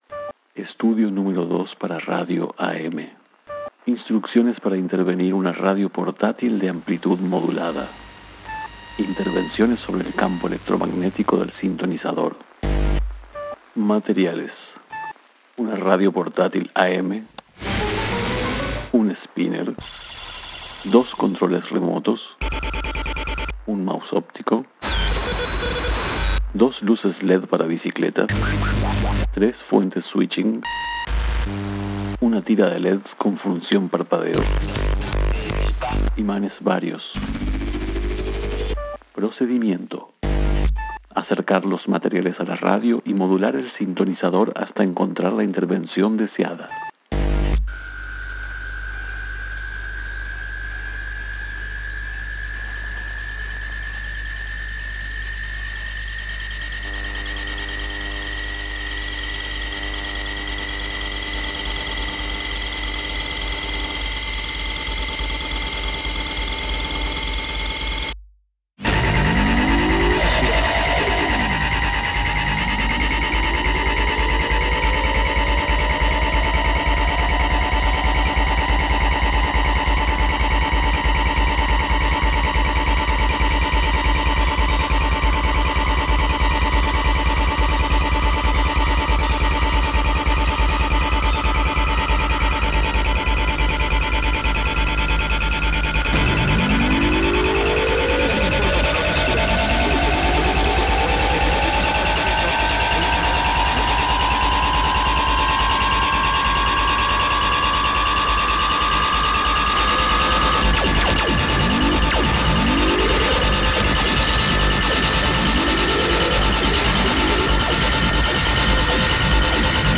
OBRAS RADIOFÓNICAS
Estas intervenciones convierten al sintonizador en un sintetizador operando en el límite entre emisión y recepción. Este trabajo experimenta con un formato poético: instructivo/pieza radiofónica convirtiendo la obra en un llamado a la acción.